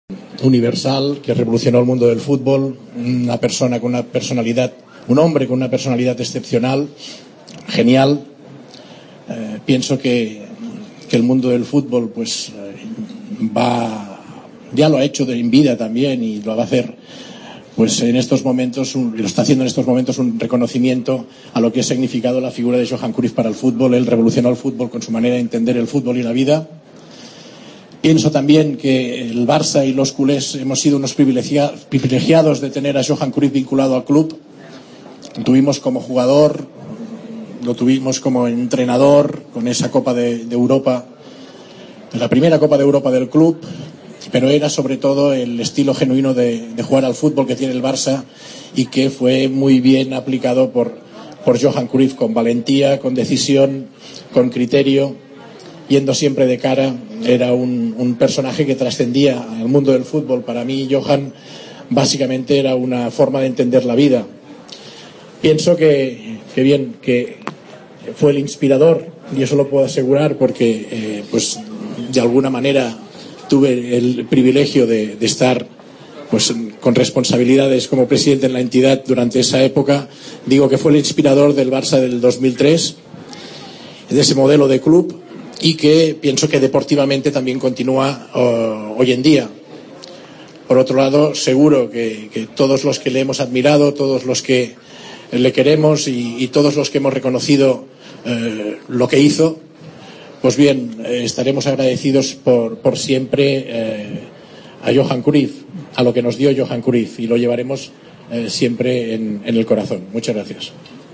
"Hemos sido unos privilegiados de tener a Cruyff como jugador y entrenador", recordó un emocionado Laporta en la despedida a Johan Cruyff.